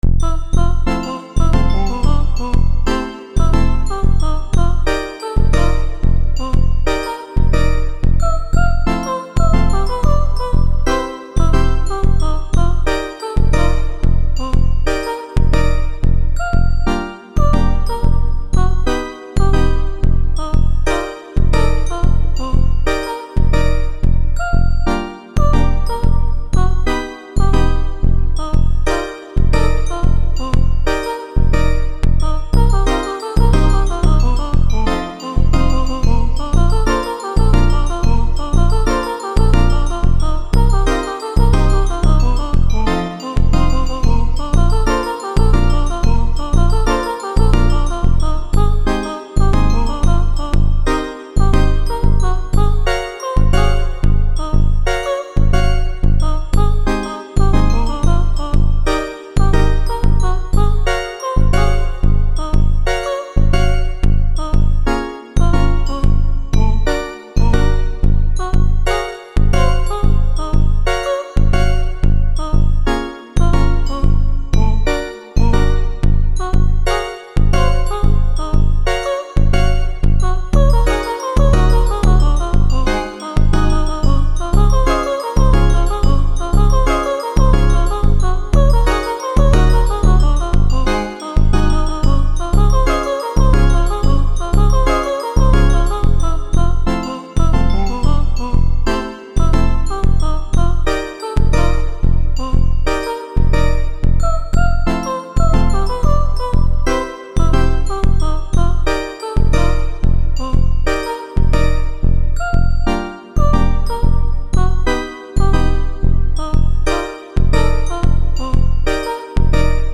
pedal_tone_2_voices_2.mp3